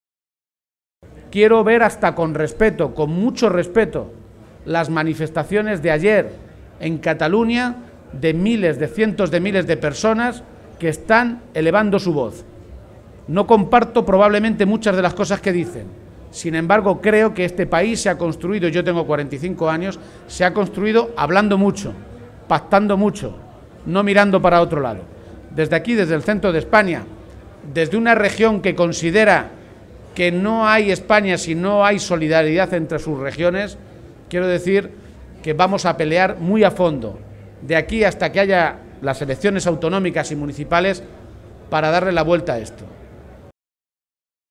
En la atención a medios de comunicación, García Page advirtió que el PSOE de Castilla-La Mancha está dispuesto a seguir el camino marcado por los socialistas en Madrid «para impedir que algo que nos ha costado tanto construir como es la sanidad de la que nos sentimos tan orgullosos se la acaben apropiando unos pocos para hacer negocio».